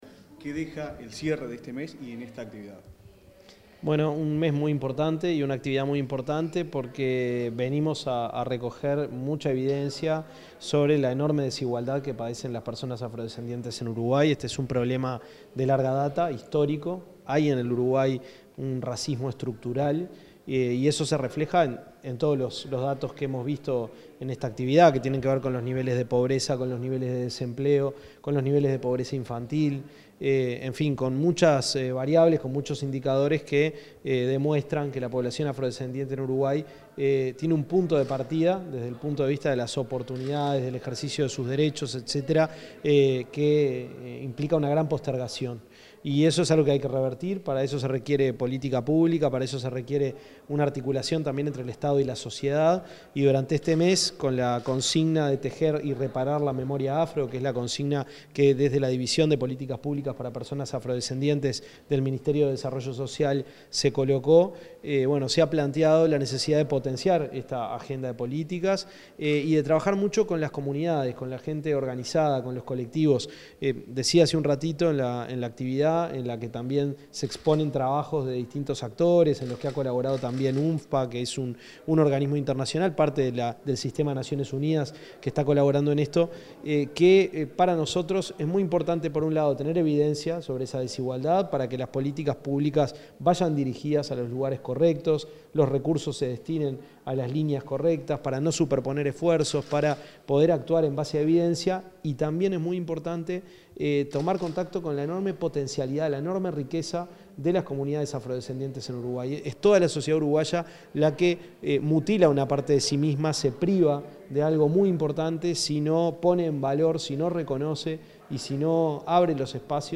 Declaraciones del ministro de Desarrollo Social, Gonzalo Civila
Al finalizar la jornada de cierre del Mes de la Afrodescendencia, el ministro de Desarrollo Social, Gonzalo Civila, realizó declaraciones.